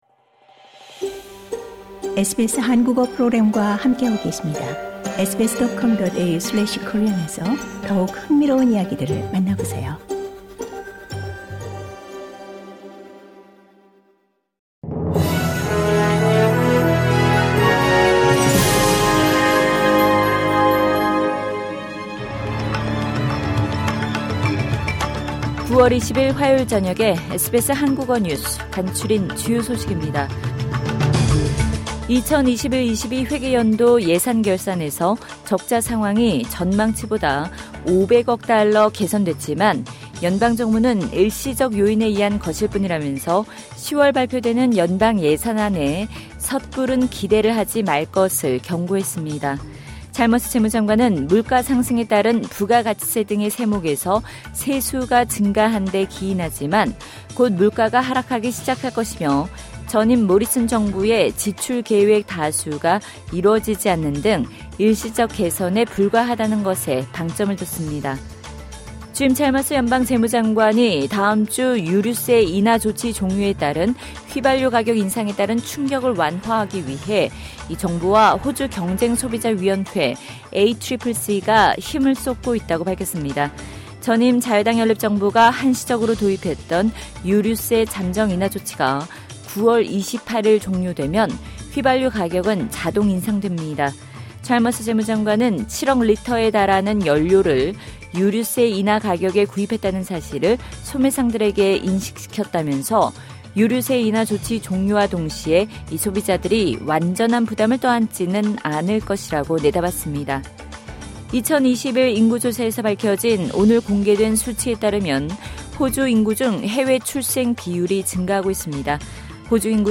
2022년 9월 20일 화요일 저녁 SBS 한국어 간추린 주요 뉴스입니다.